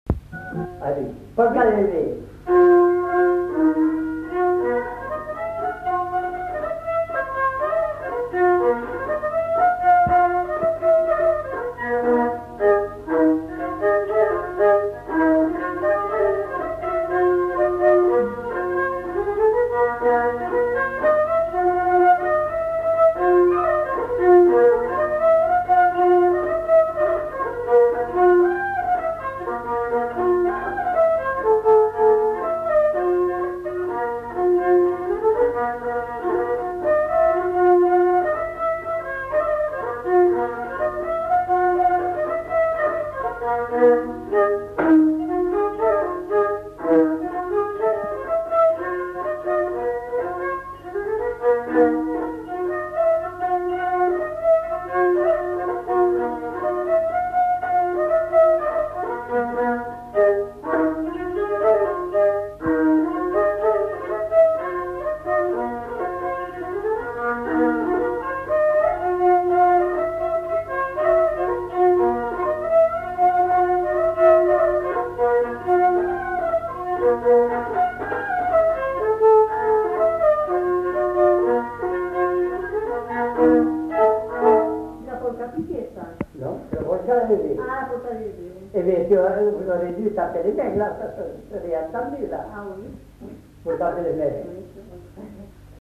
Lieu : Haut-Mauco
Genre : morceau instrumental
Instrument de musique : violon
Danse : polka des bébés